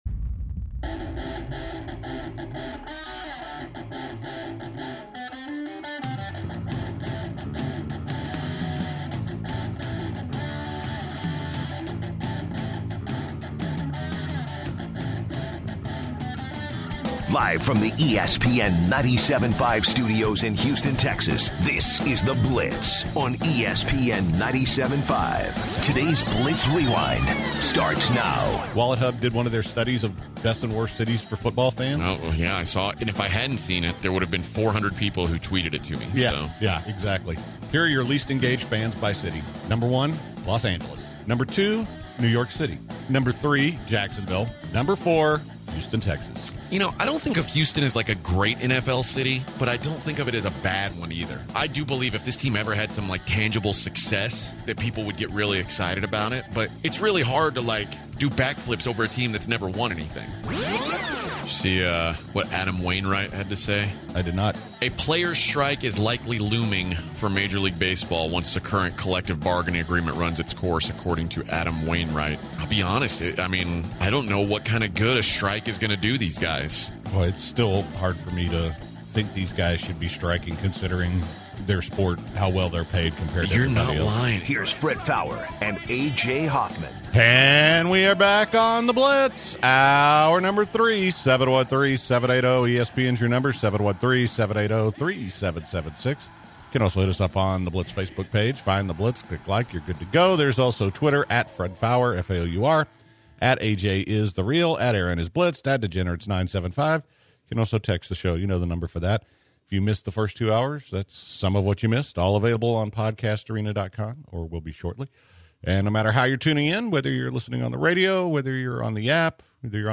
The guys answer some calls about whether or not they would trade James Harden for AD before wrapping up on a “Dumb Ass Report”